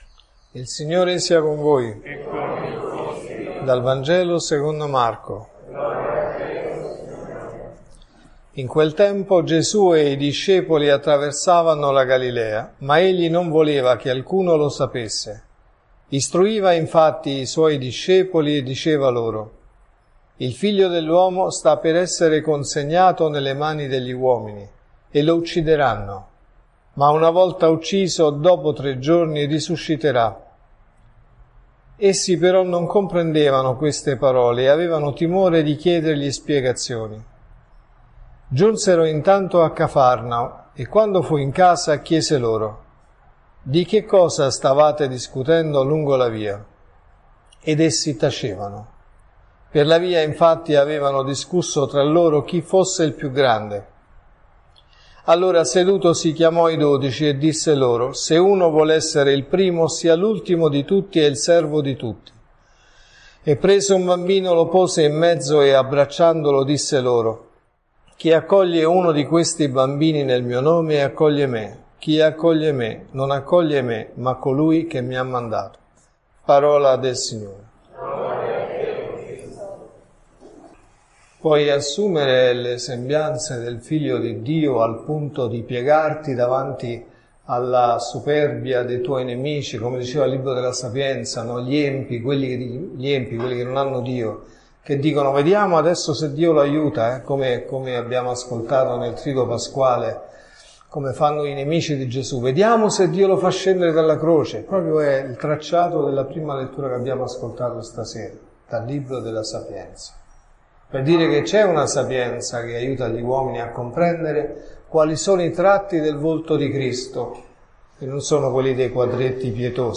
Omelie